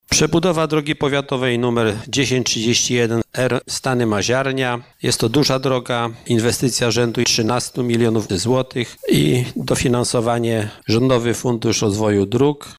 Starosta Janusz Zarzeczny zapowiadając tę inwestycję mówił o skali tego zadania: